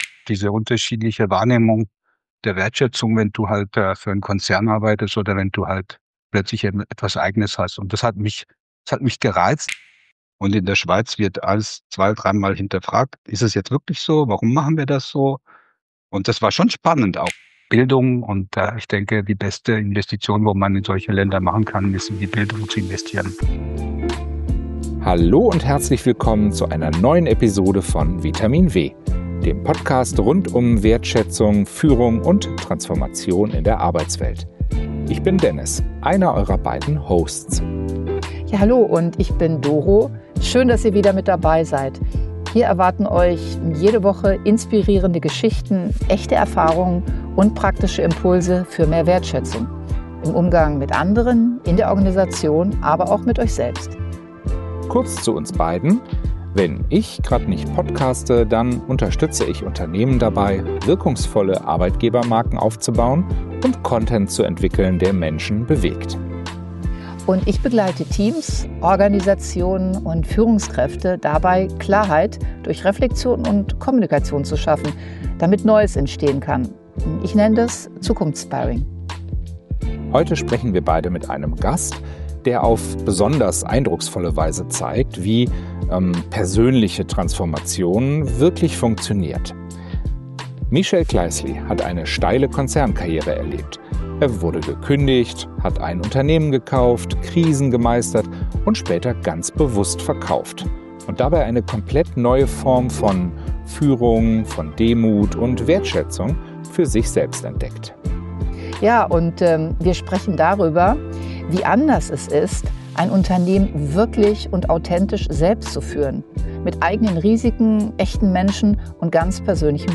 Heraus kam ein ehrliches, ruhiges Gespräch über Wandel von innen – für alle, die Führung, Unternehmertum und Transformation neu denken wollen.